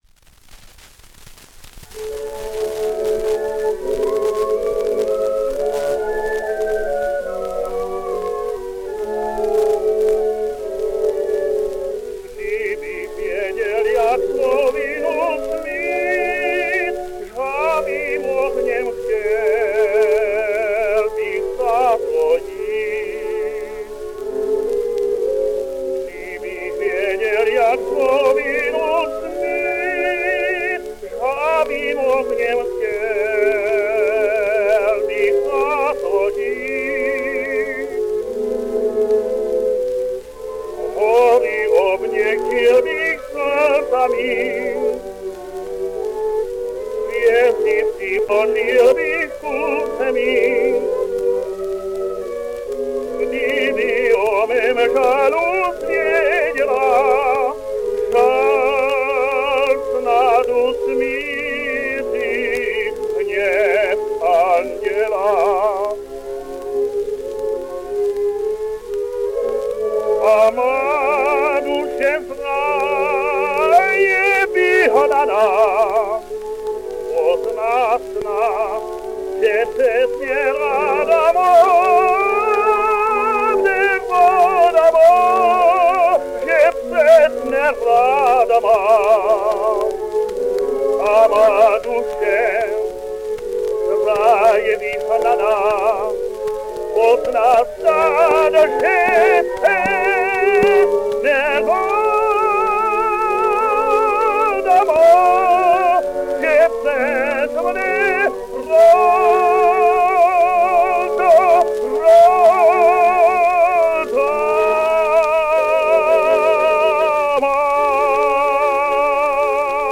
While those four were comprimario parts, and while he had a few other comprimario parts in his repertory, as well, he sang mostly lead roles: Don Ottavio, Wilhelm Meister, Max, Don José, Massenet's des Grieux, Gounod's Faust, Alfredo, Almaviva, Lenskij, Prince in Dvořák's Rusalka, Števa, Brouček...